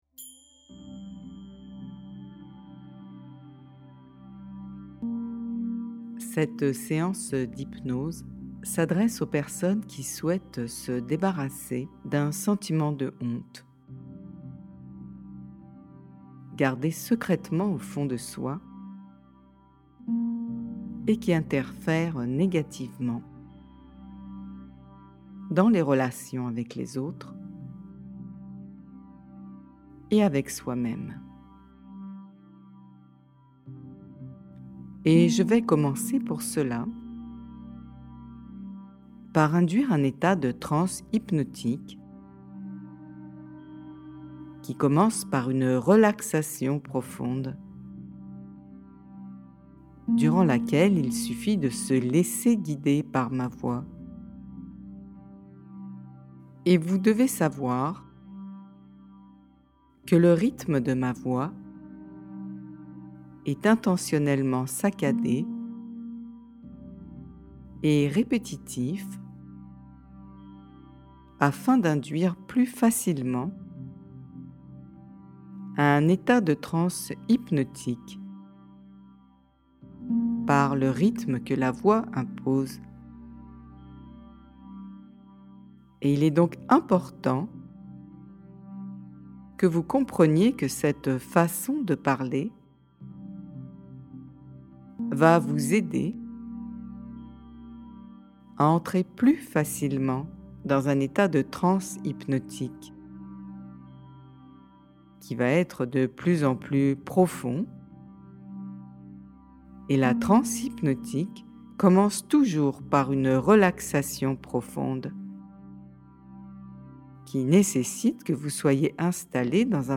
Surmonter la honte - Autohypnoses mp3